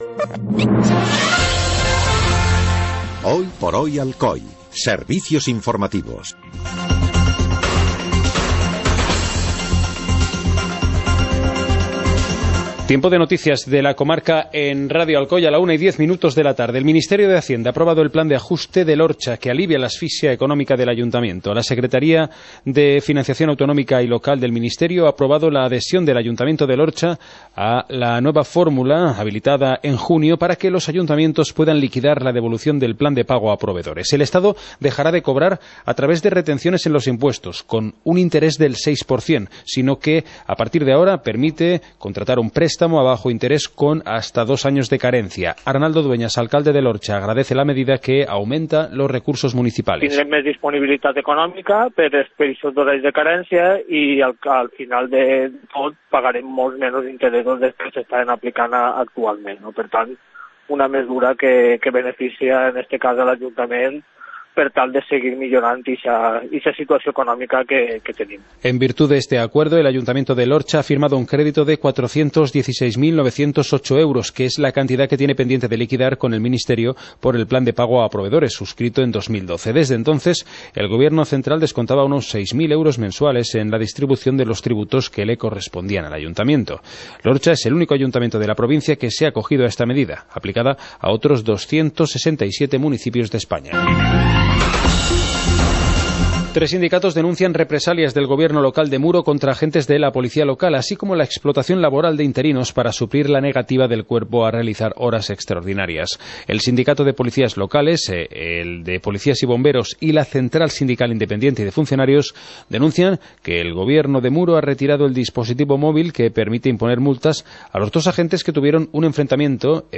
Informativo comarcal - martes, 28 de noviembre de 2017